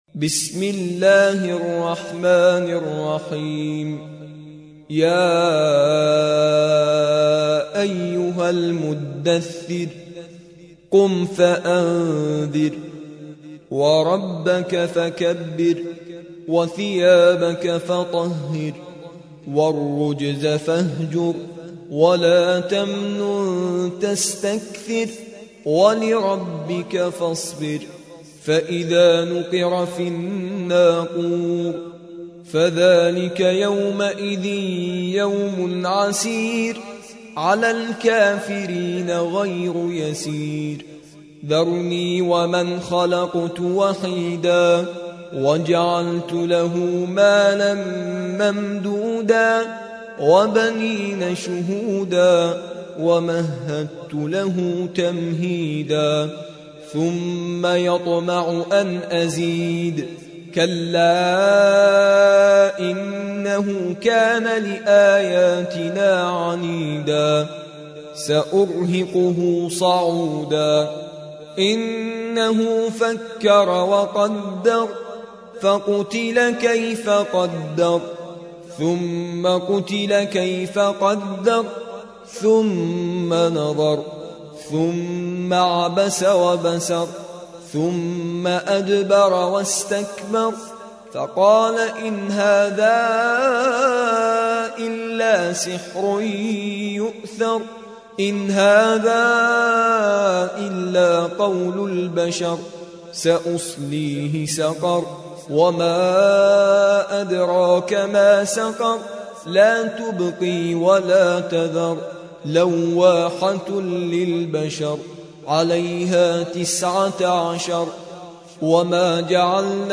74. سورة المدثر / القارئ